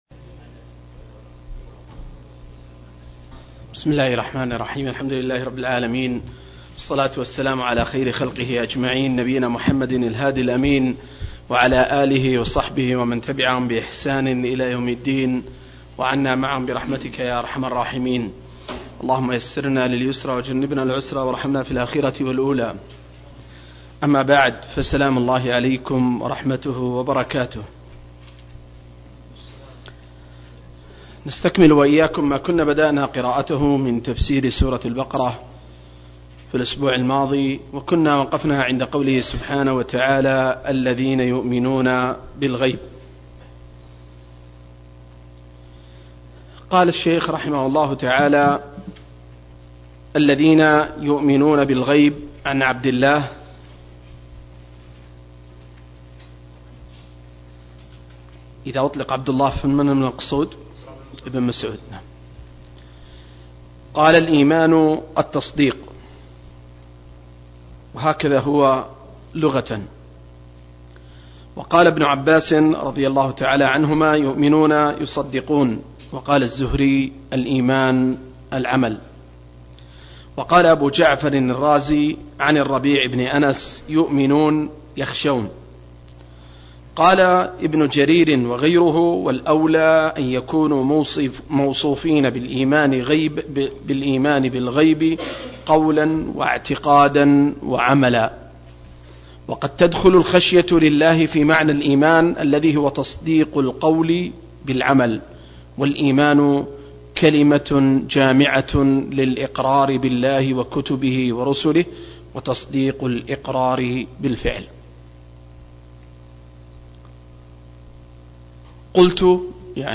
008- عمدة التفسير عن الحافظ ابن كثير رحمه الله للعلامة أحمد شاكر رحمه الله – قراءة وتعليق –